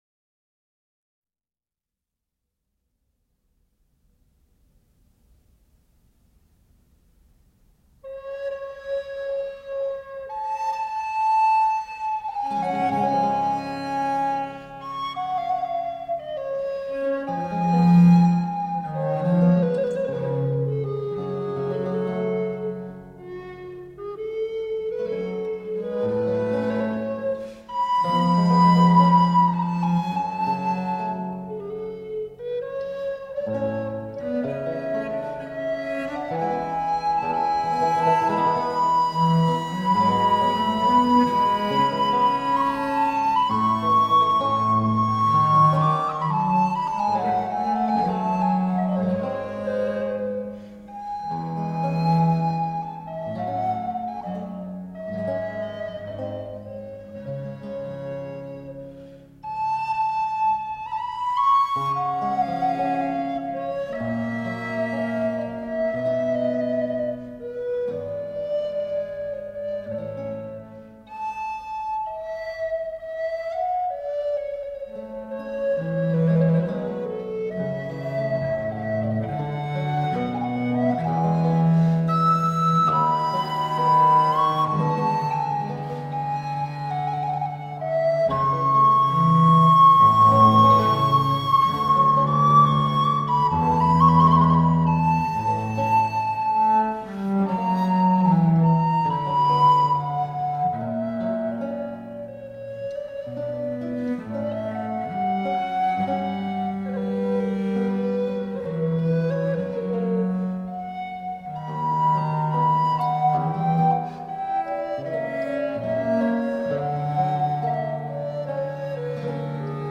Reflective, historically-informed performance on the lute.
Recorder, Theorbo, Viola da Gamba